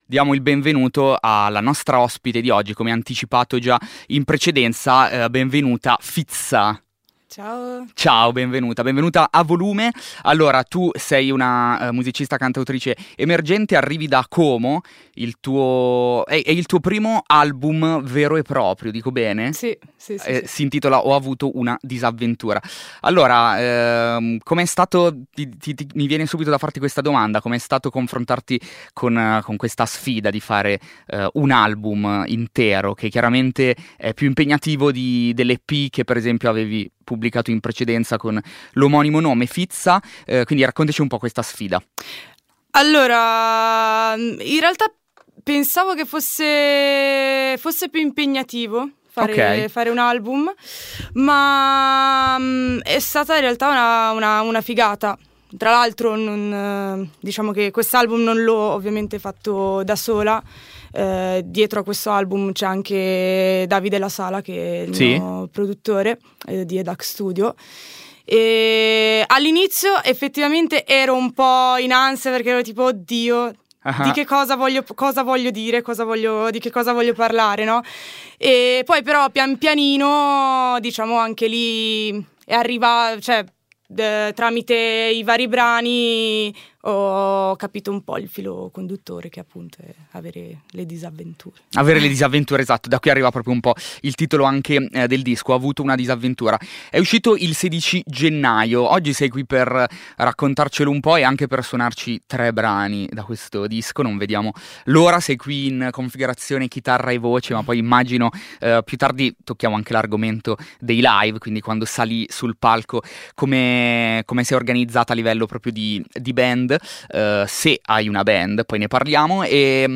eseguiti con chitarra acustica e voce
L'intervista